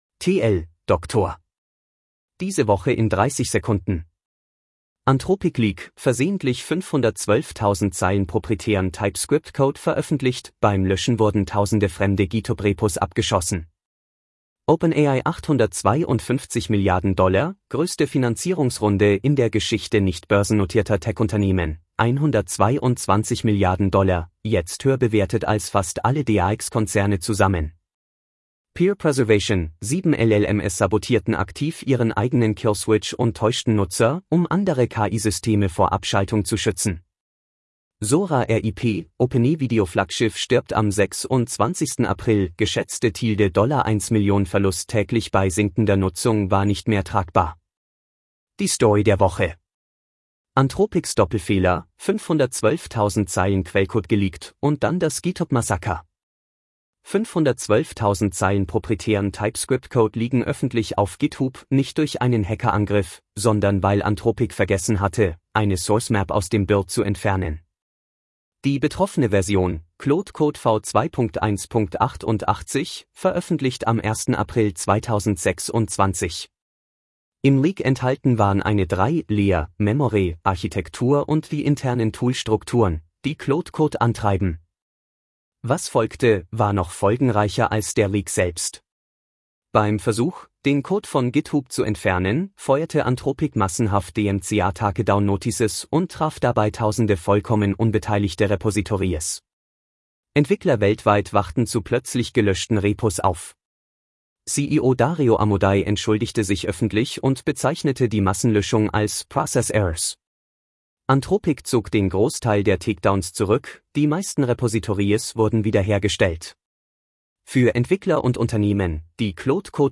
Vorgelesen mit edge-tts (de-DE-ConradNeural)